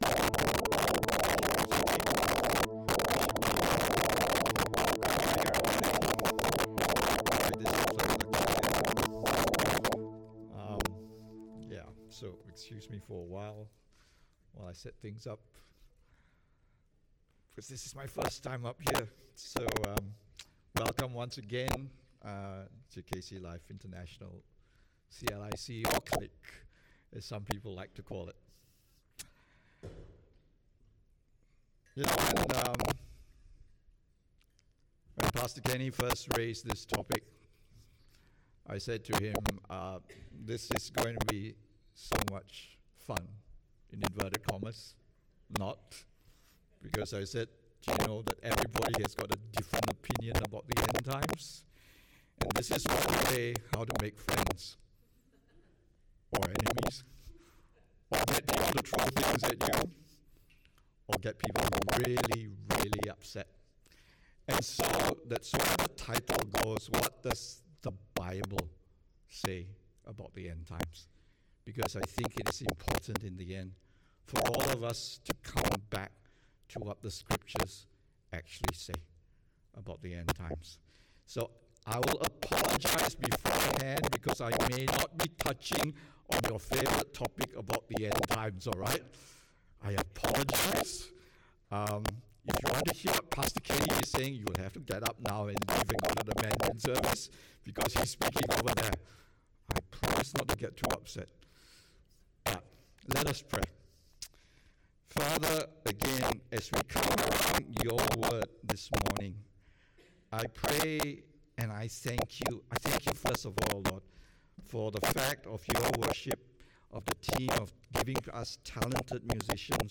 English Sermons | Casey Life International Church (CLIC)
English Worship Service - 3 July 2022